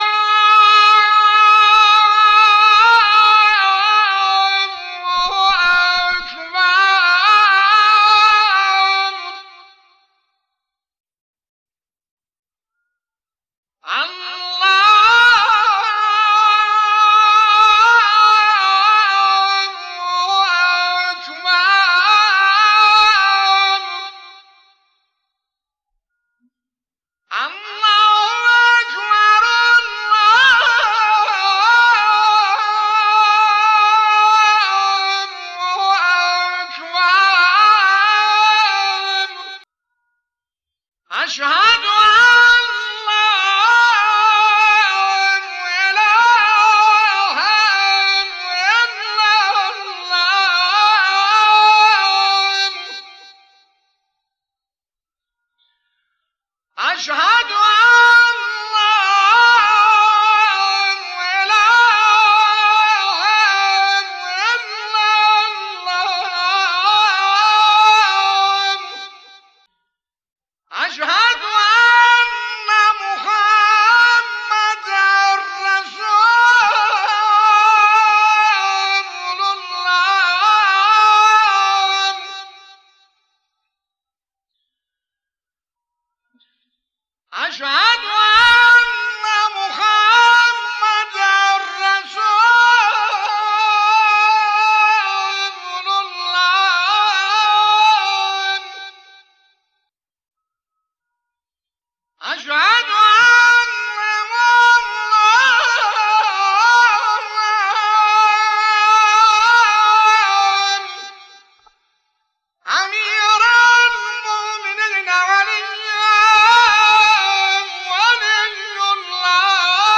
اما خب بازهم فایل اصلی و استودیویی این اذان رو پیدا نکردم و این چیزی که مشاهده میکنید و میشنوید با تغییرات و تنظیمات دستی خودم درست کردم تا در خدمت شما عزیزان و دوست داران قرار بگیره.
azan_ramezan.mp3